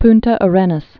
(pntə ə-rĕnəs, pntä ä-rĕnäs)